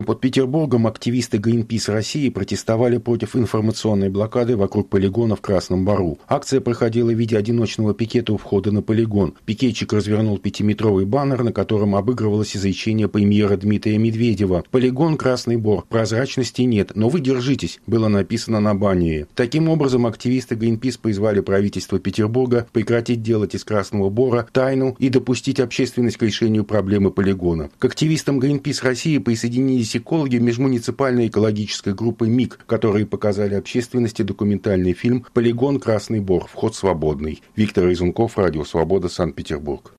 Рассказывает